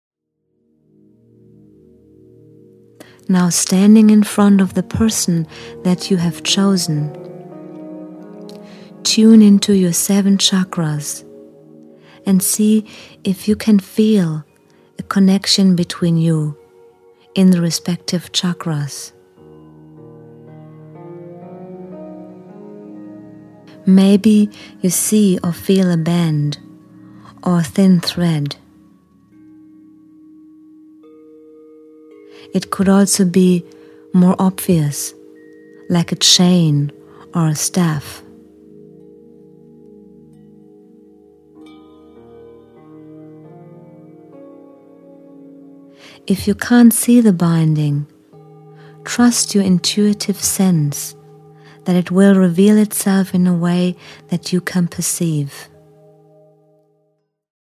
Energy work/Meditation for Clearing and Getting Go of Old Karmic Relationships.
Meditation CD